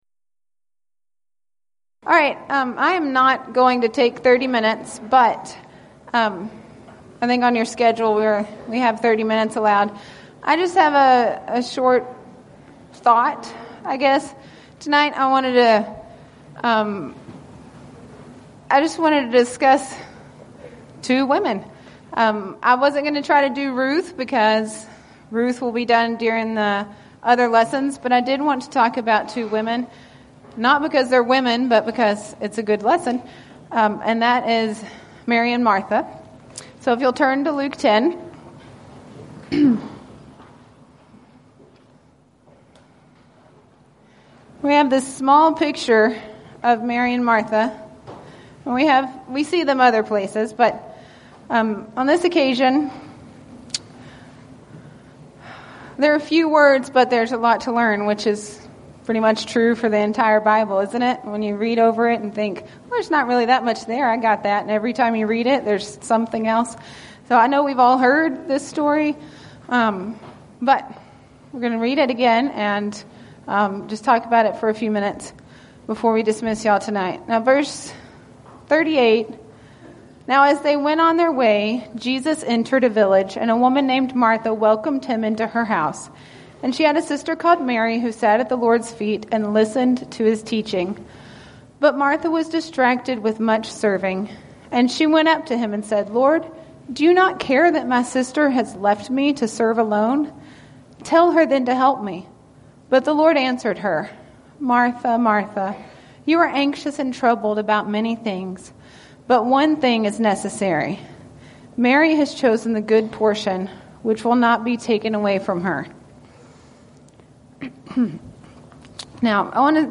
Title: Devotional
Event: 7th Annual Texas Ladies in Christ Retreat Theme/Title: Studies in Ruth & Judges